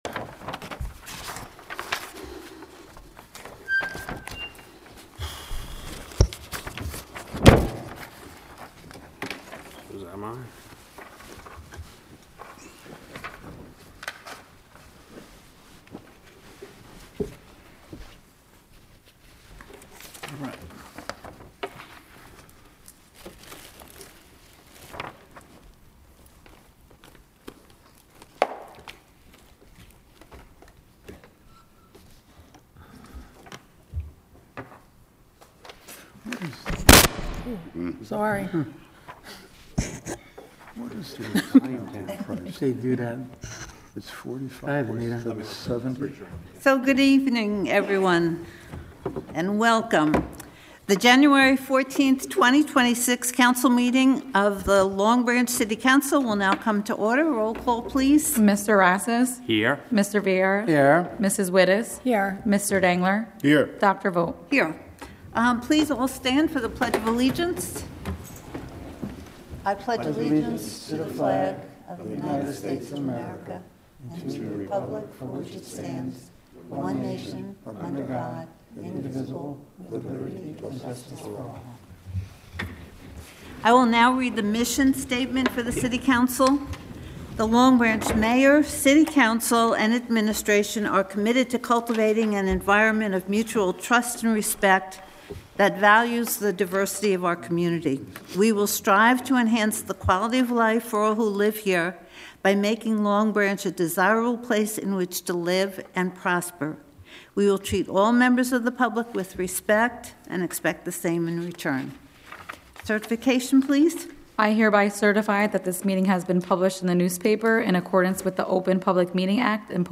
January 14, 2026, City Council Meeting Materials. Media